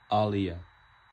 Captions English Proper pronunciation
En-usAaliyah.ogg